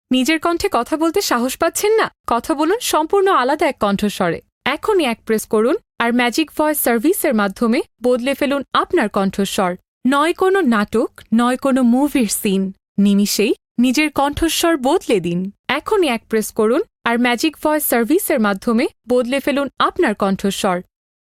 宣传片